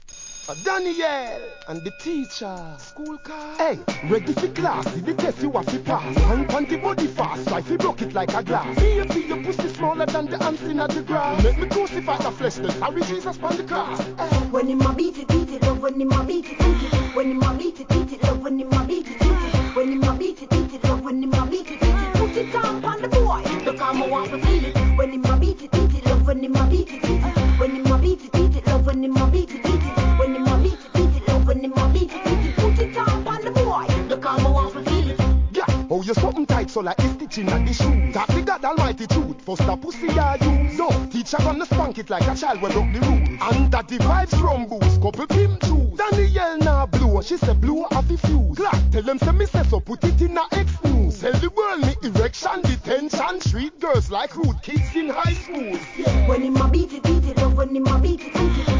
REGGAE
キャッチーなサビもGOOD!